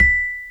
SFX / Xylophone C Major